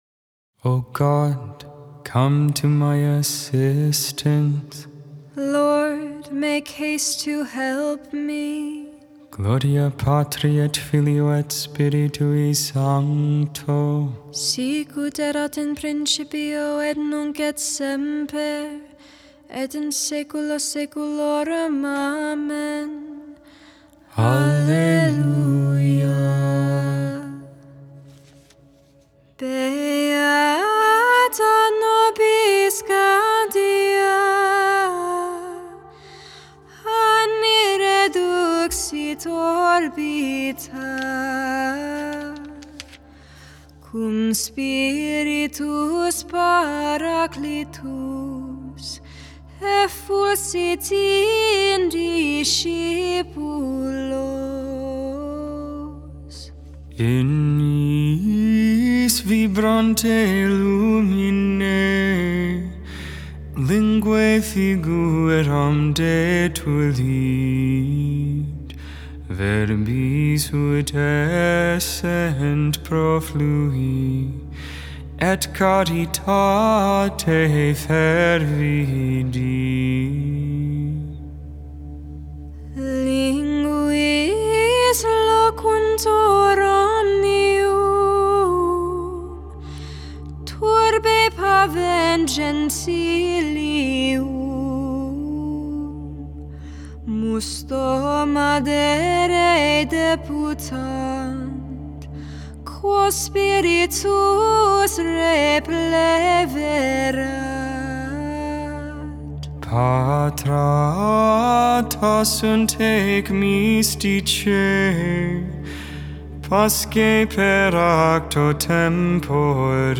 The Liturgy of the Hours: Sing the Hours 5.19.24 Lauds, Sunday Morning Prayer May 18 2024 | 00:18:16 1x 00:00 / 00:18:16 Subscribe Share Spotify RSS Feed Share Link Embed